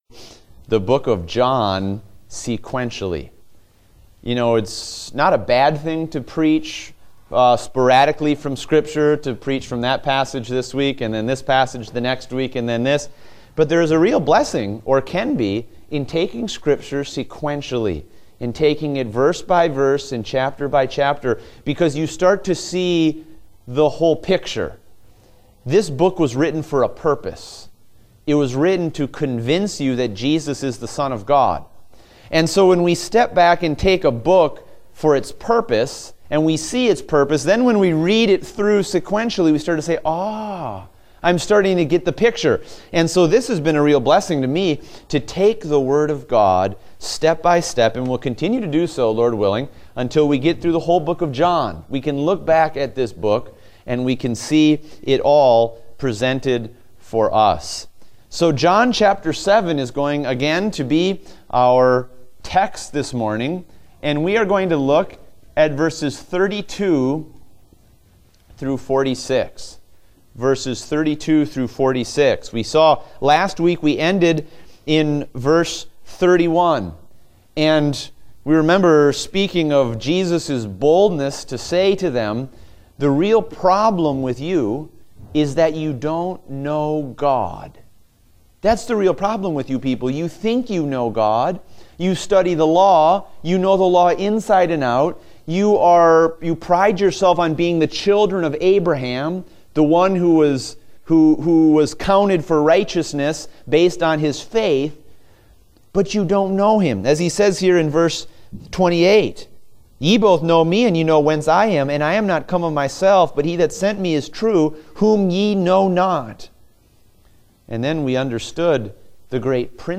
Date: September 11, 2016 (Adult Sunday School)